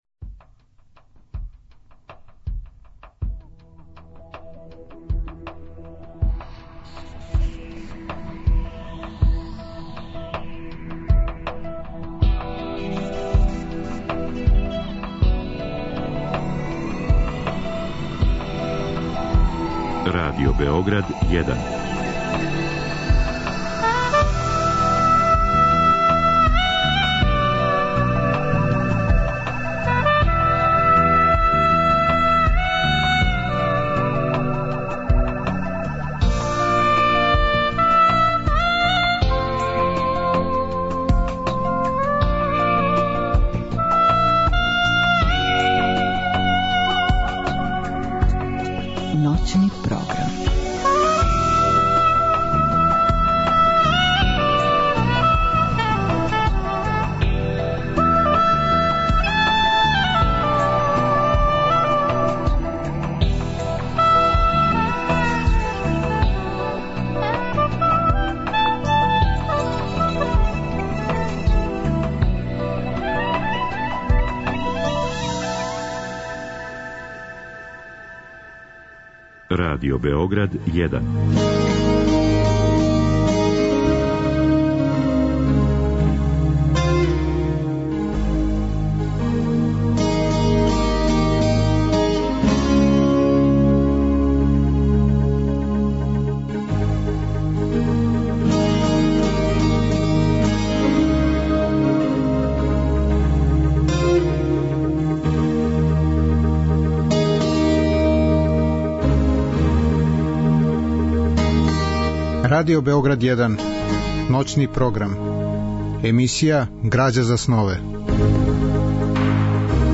Разговор и добра музика требало би да кроз ову емисију и сами постану грађа за снове.
У емисији ГРАЂА ЗА СНОВЕ у ноћи између уторка и среде гост је књижевник Александар Гаталица. Биће речи о његовим причама и романима, о роману Велики рат, о делима класичне књижевности и о односу музике и књижевног стварања.